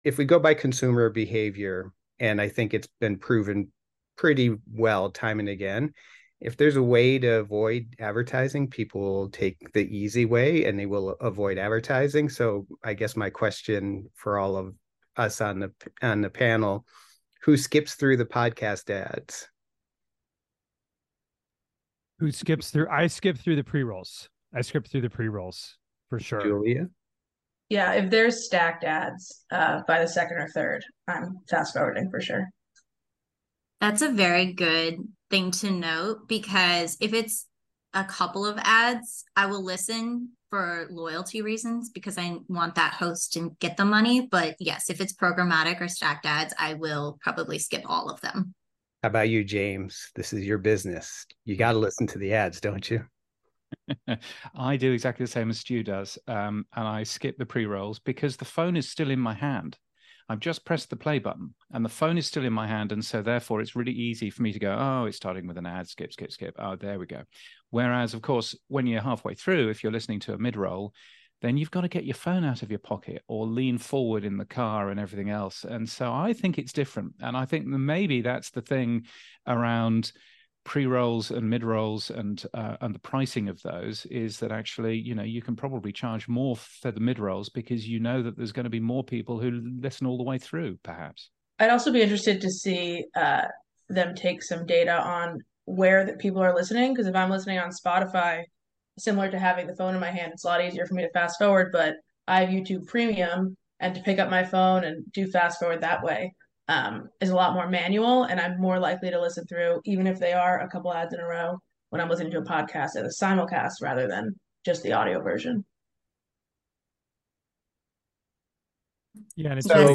(podcast ad agency panel)